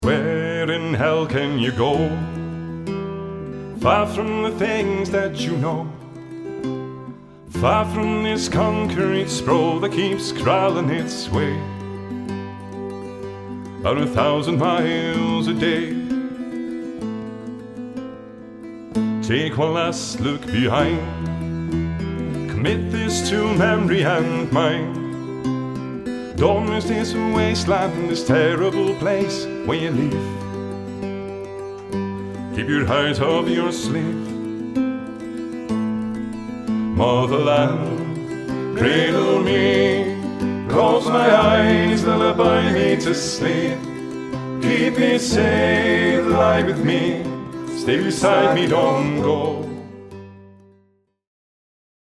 Celtic Folk Music